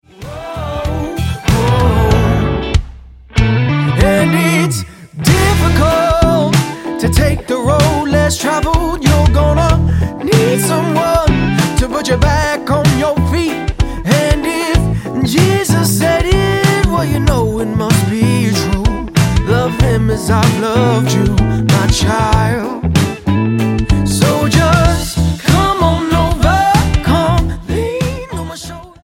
STYLE: Pop
blues-tinged stomper